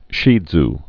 (shē dz)